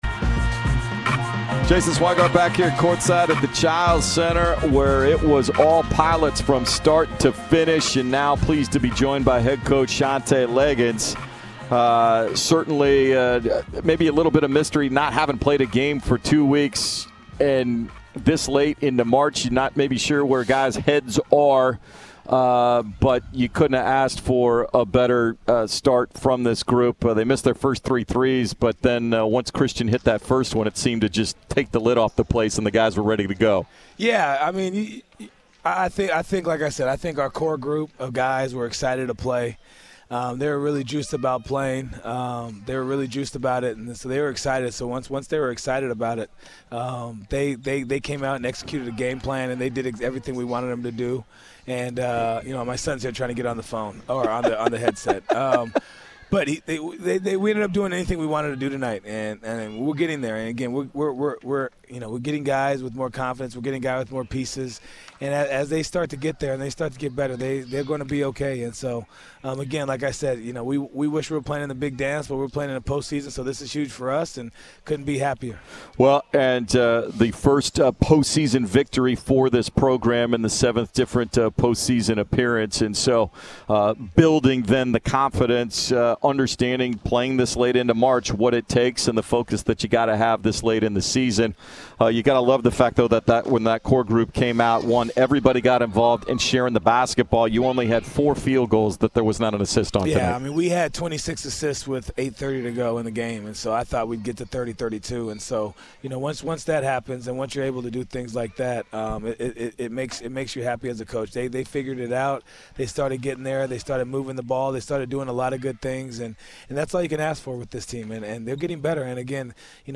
Men's Basketball Interviews